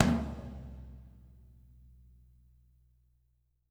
-TOM 2G   -R.wav